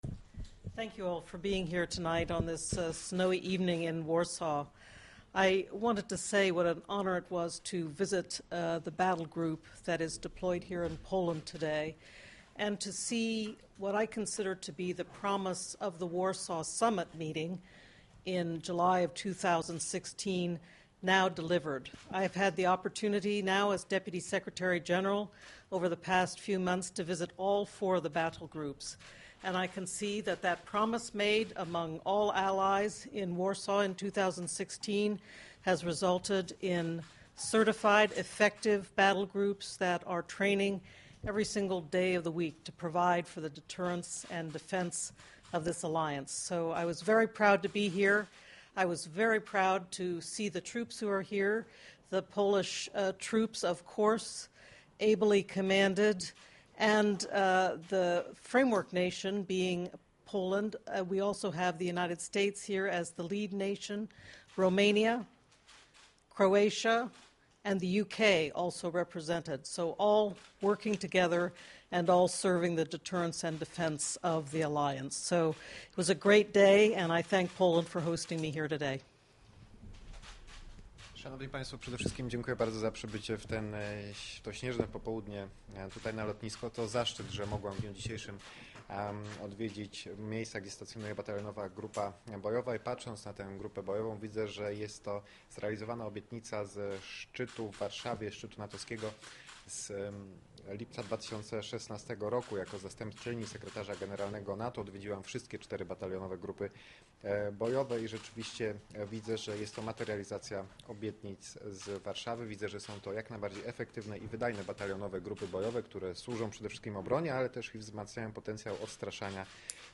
Press conference
by NATO Deputy Secretary General Rose Gottemoeller at Warsaw Military Airport